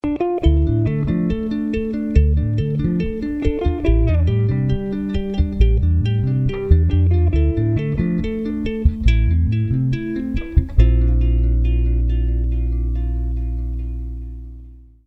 mp3-handy-klingelton